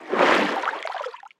Sfx_creature_snowstalker_swim_02.ogg